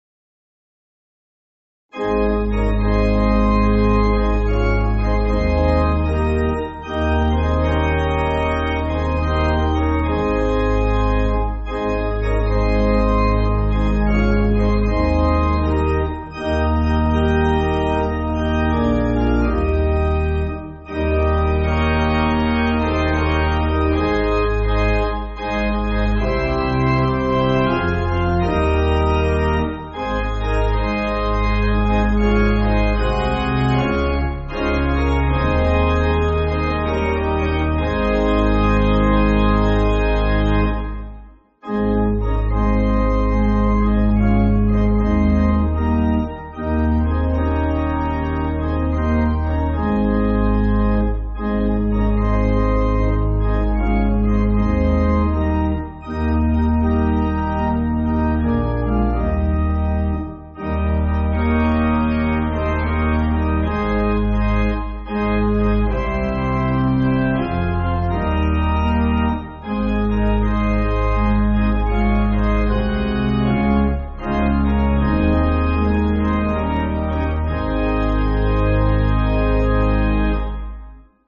(CM)   4/Ab